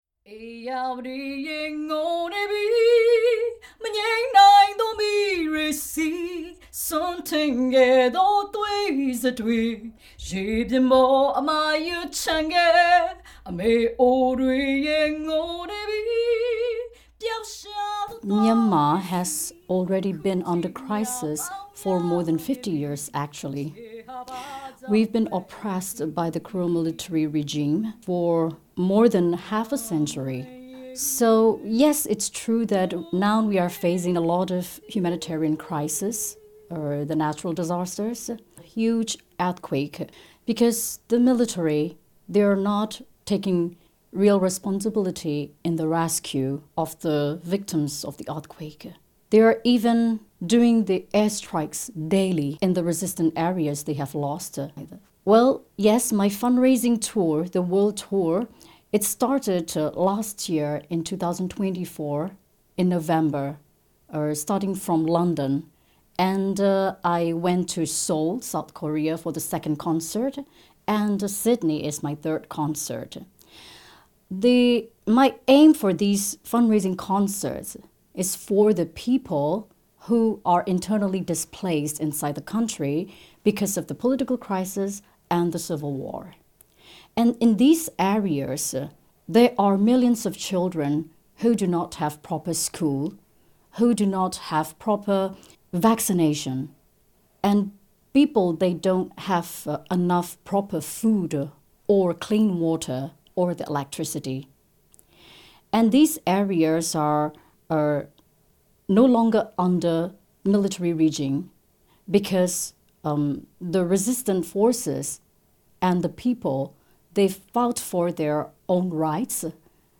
ဖြူဖြူကျော်သိန်းကို SBS studio မှာအင်တာဗျူးလုပ်ထား ပါတယ်။
SBS စတူဒီယိုမှာ ဖြူဖြူကျော်သိန်း အင်တာဗျူး လုပ်နေစဉ်။